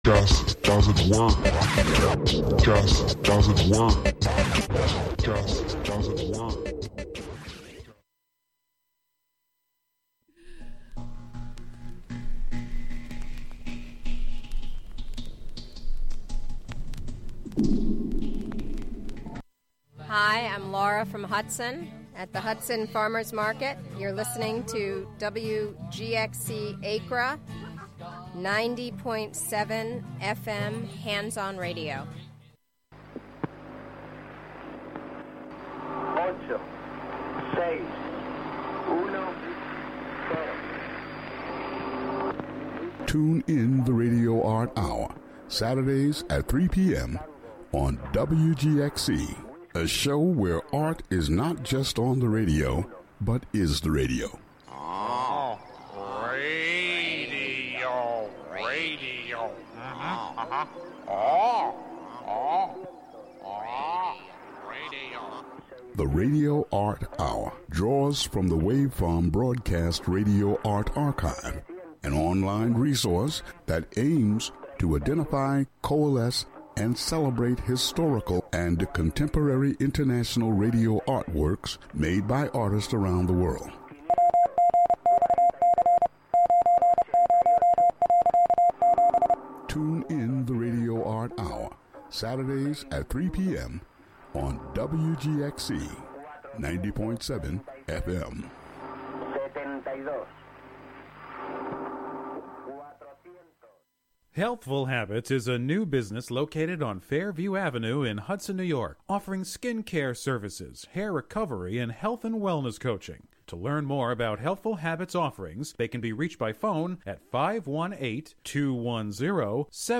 But, with four ears between them, they are able to work their witchcraft to bring you music that lifts you up and flies you home.